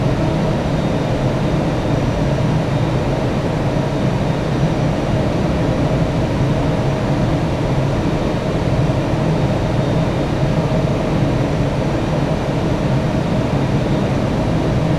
cfm-idle2.wav